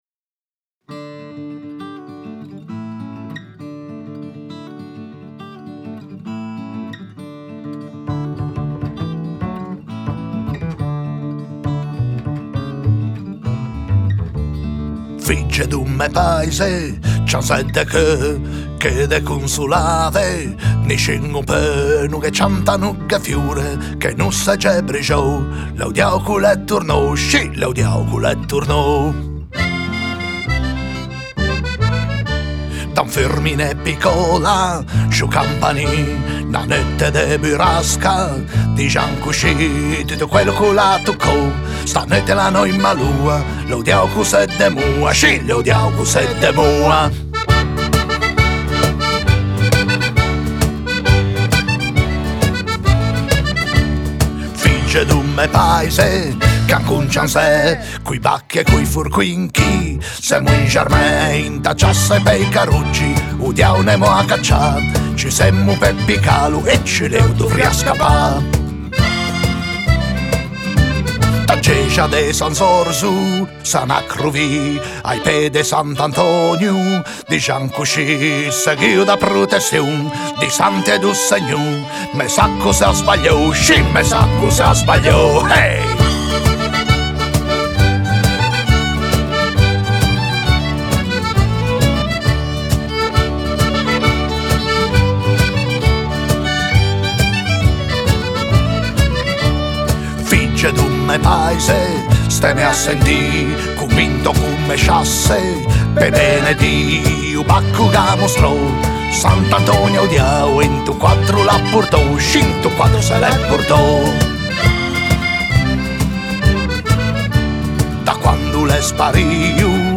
Il primo album in studio!